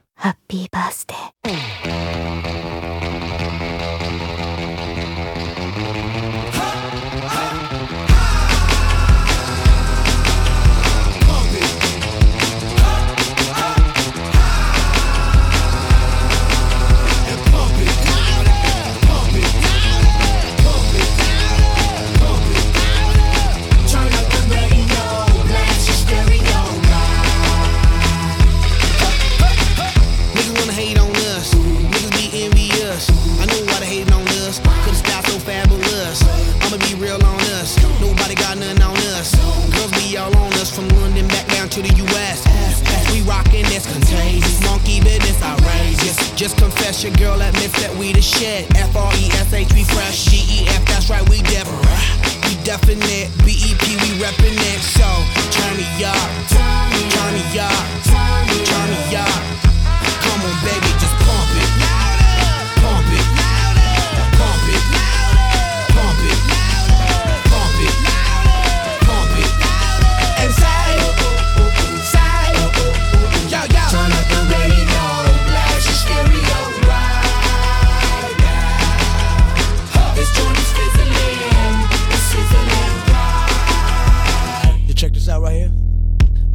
BPM153-153
Audio QualityCut From Video